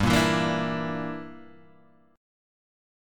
G Minor 6th